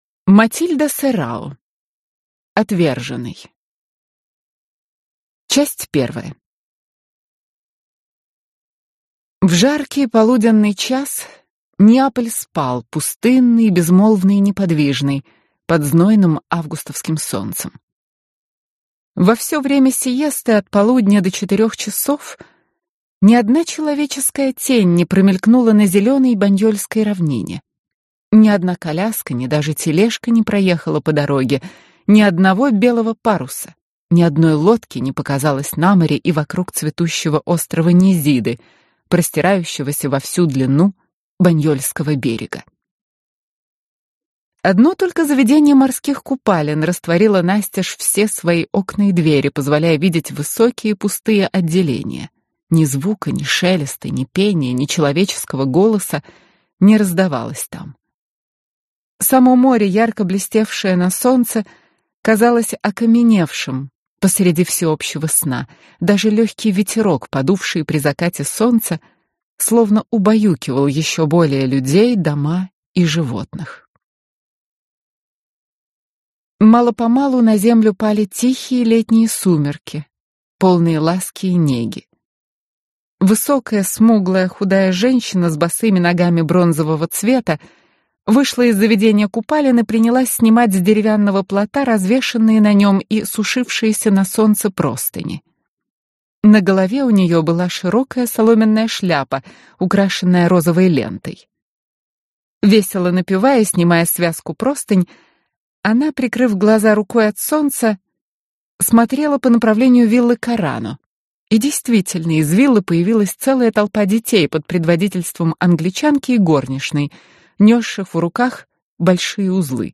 Аудиокнига Отверженный | Библиотека аудиокниг